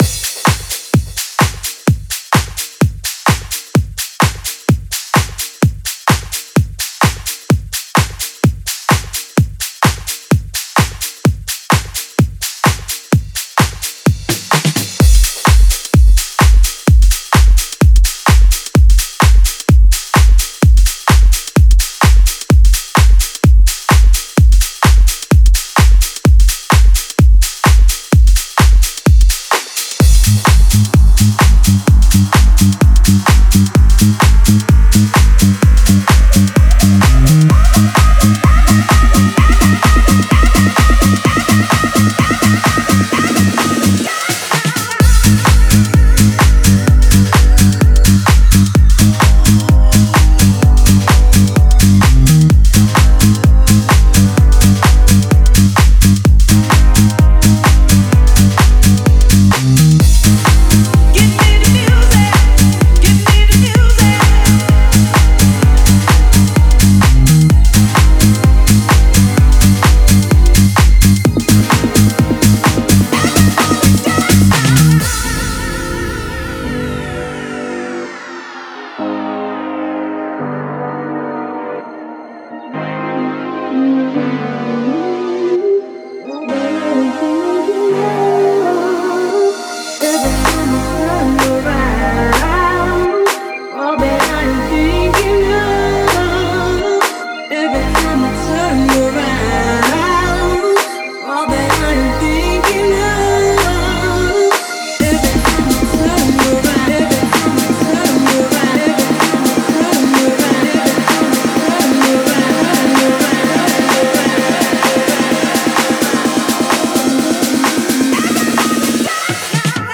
это энергичный трек в жанре хаус